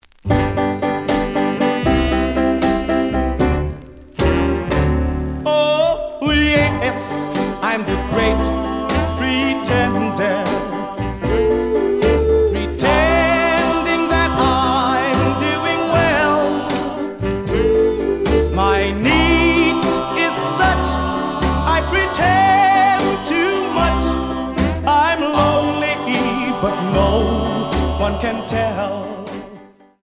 試聴は実際のレコードから録音しています。
●Genre: Rhythm And Blues / Rock 'n' Roll
●Record Grading: VG (A面のラベルにステッカー。盤に若干の歪み。プレイOK。)